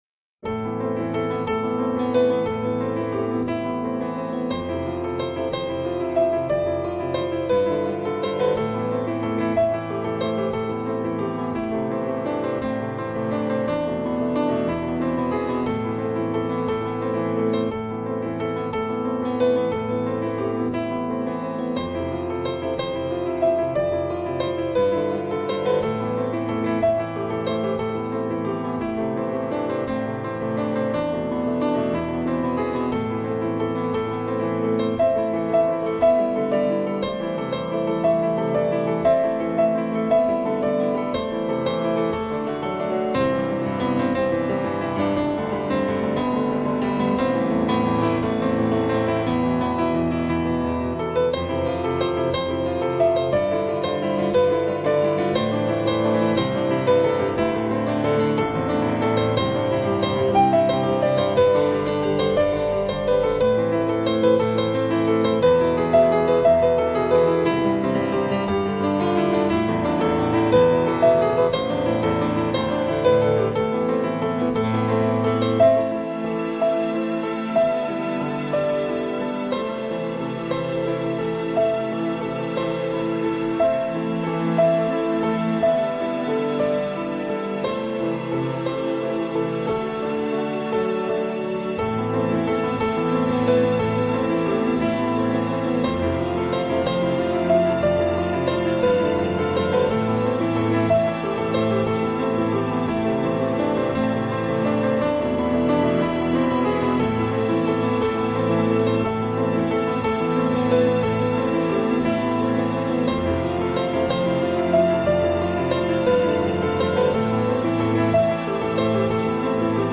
На мобилку » Mp3 » Музыка из фильмов